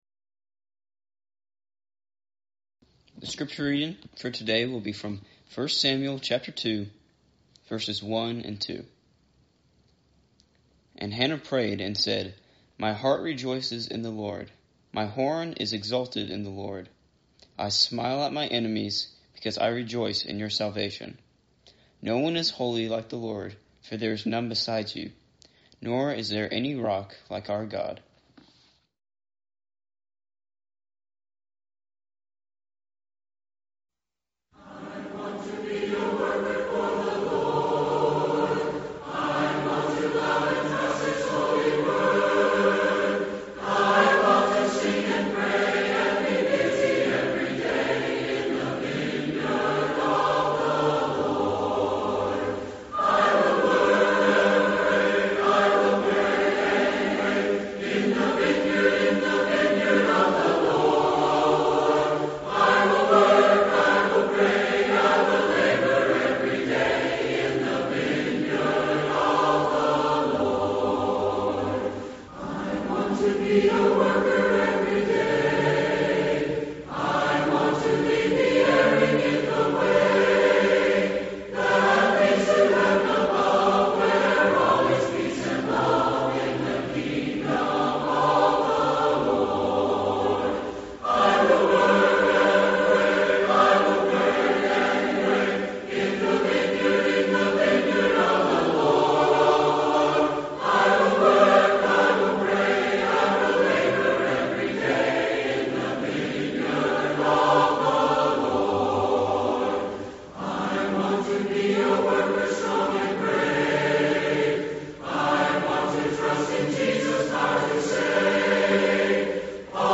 Series: Eastside Sermons
Eastside Sermons Service Type: Sunday Morning Preacher